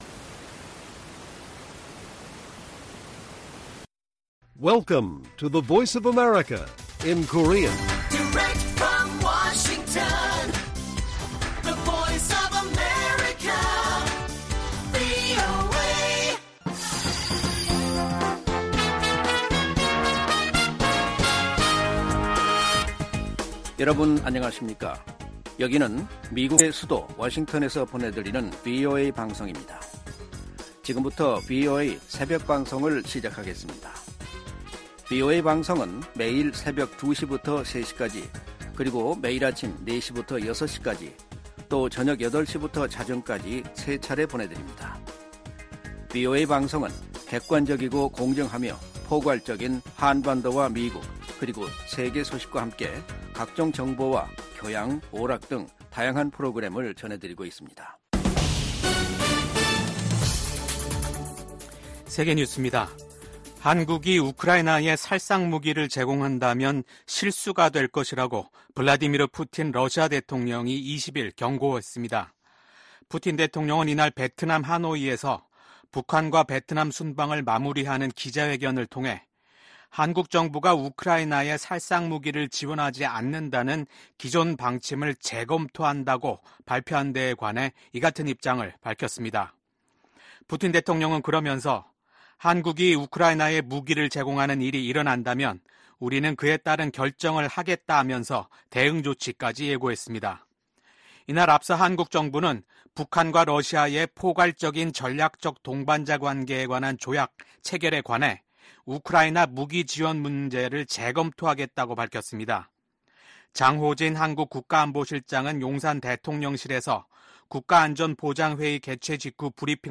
VOA 한국어 '출발 뉴스 쇼', 2024년 6월 21일 방송입니다. 북한과 러시아가 새 조약을 통해 어느 한쪽이 무력침공을 받아 전쟁 상태에 놓이면 지체 없이 군사적 원조를 제공하기로 했습니다. 미국 정부는 이에 대해 한반도 평화와 안정, 국제 비확산 체제, 러시아의 잔인한 우크라이나 침략 전쟁 등을 지적하며 심각한 우려 입장을 나타냈습니다. 한국 정부도 국제사회의 책임과 규범을 저버리는 행위라며 이를 규탄한다고 밝혔습니다.